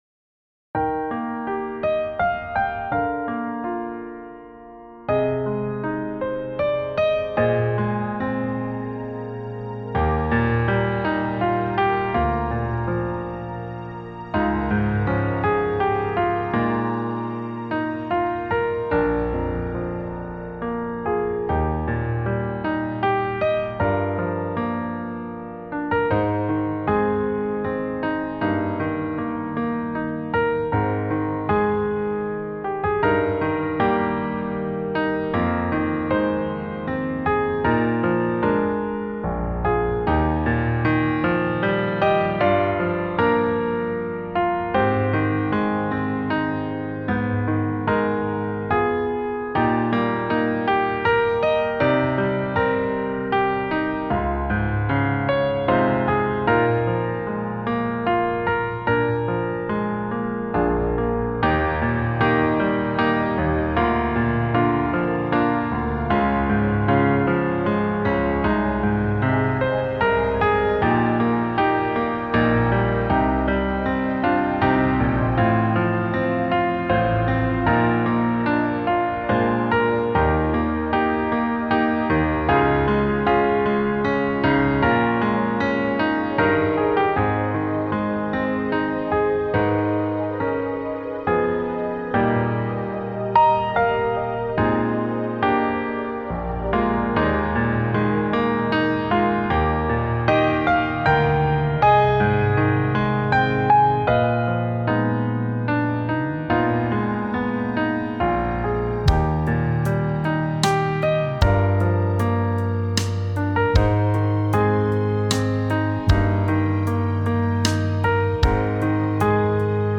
Key: E BPM: 78 Time sig: 3/4 Duration:  Size: 11MB
Choral Worship
Choir